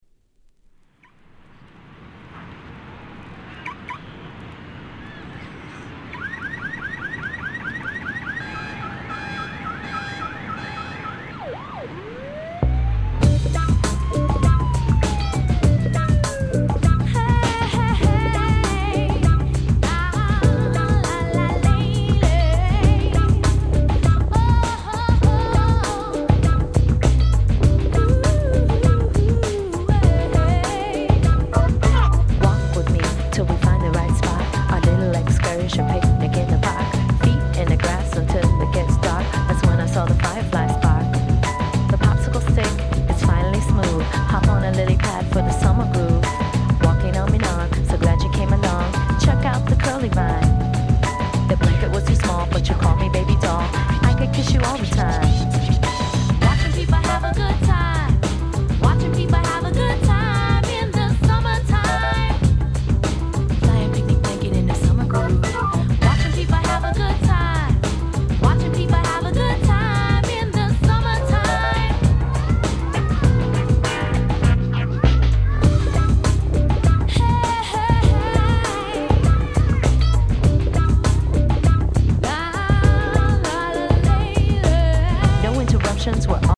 94' Nice R&B !!